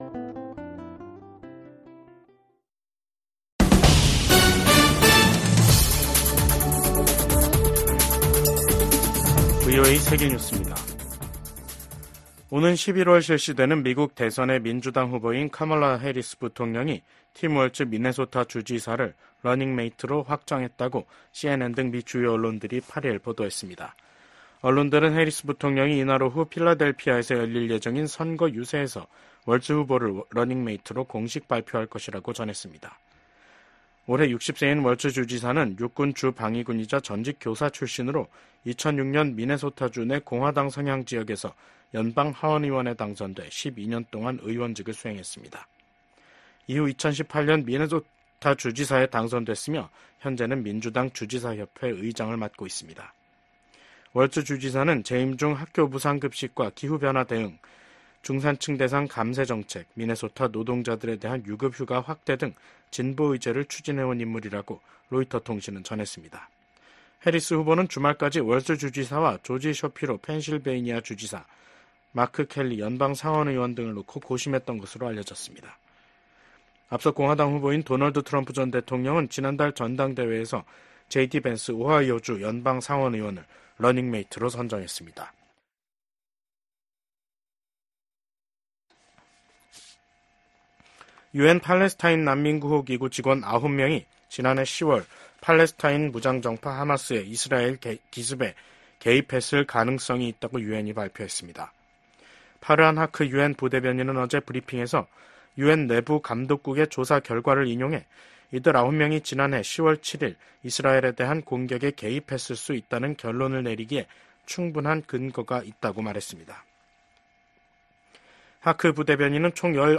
VOA 한국어 간판 뉴스 프로그램 '뉴스 투데이', 2024년 8월 6일 3부 방송입니다. 북한이 신형 전술 탄도미사일 발사대를 공개한 것과 관련해 미국의 전문가들은 북한의 점증하는 위협에 맞서 탄도미사일 방어 역량을 강화해야 한다고 지적했습니다. 압록강 유역 수해 복구에 전 사회적 인력 동원에 나선 북한이 외부 지원 제의는 일절 거부하고 있습니다.